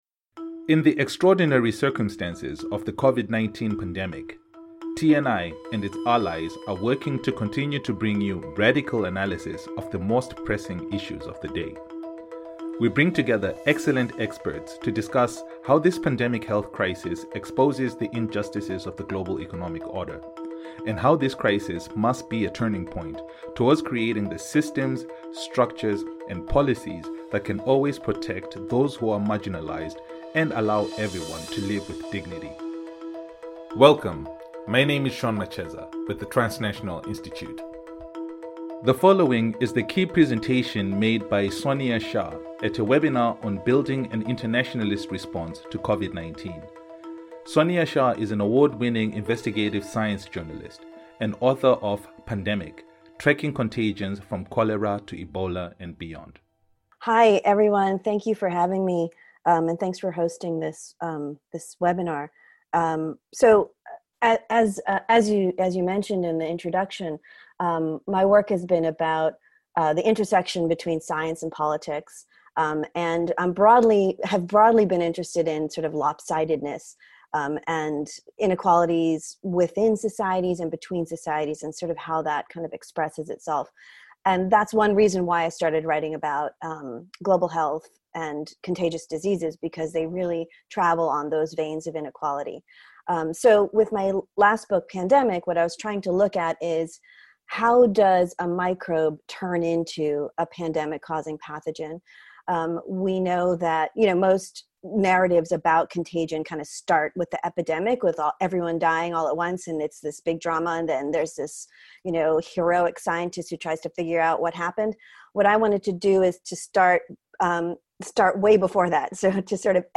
This episode is an extract from the first in our series of webinars focused on Covid-19, which featured a presentation by Sonia Shah, author of Pandemic: Tracking contagions from Cholera to Ebola and Beyond (2017), as well as contributions from experts on public health systems...